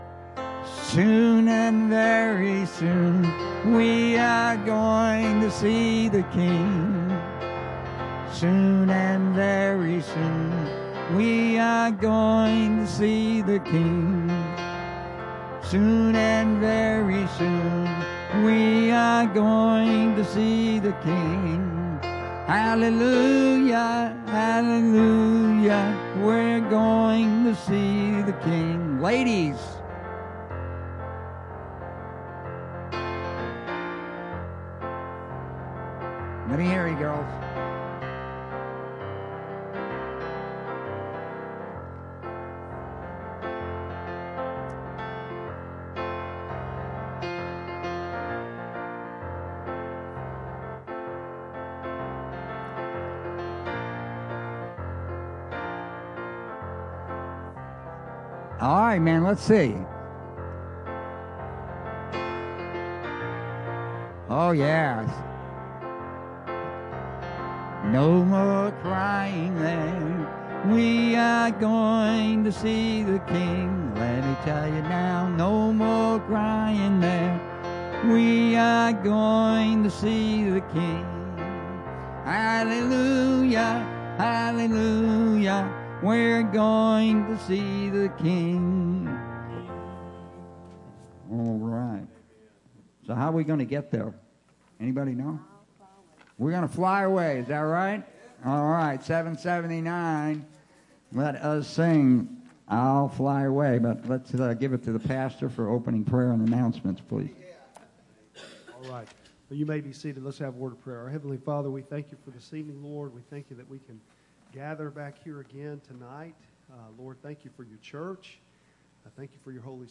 Bible Text: John 13:1-17 | Preacher